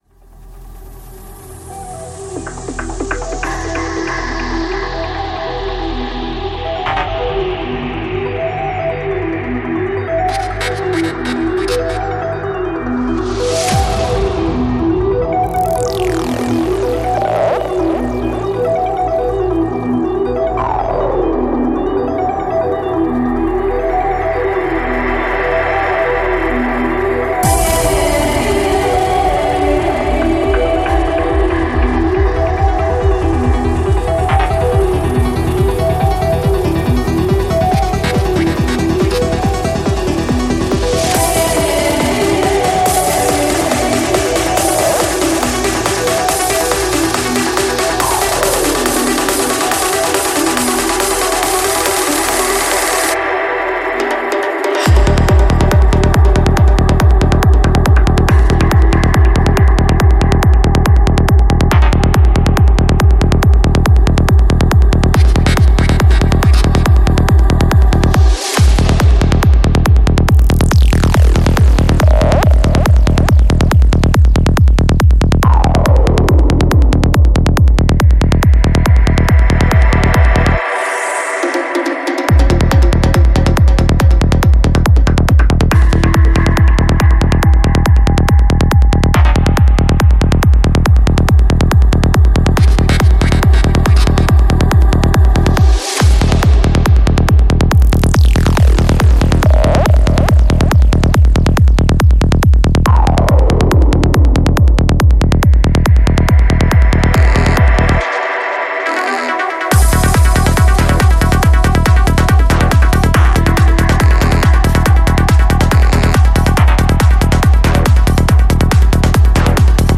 Жанр: Psytrance